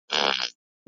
Quick And Squelchy Fart - Bouton d'effet sonore